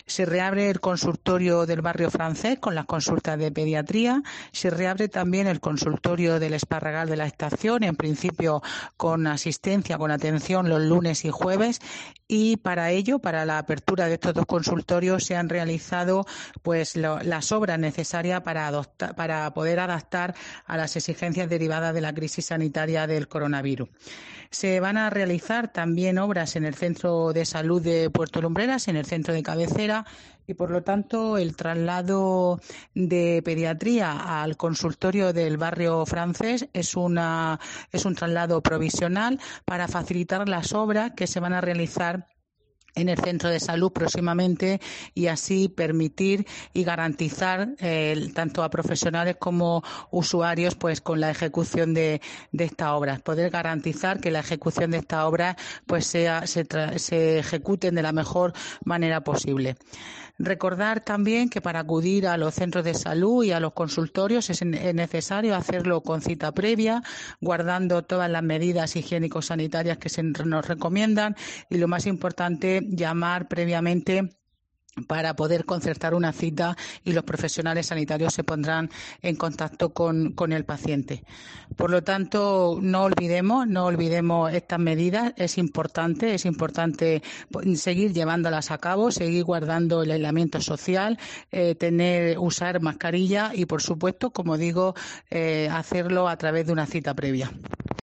María Angeles Túnez, alcaldesa de Puerto Lumbreras sobre consultorios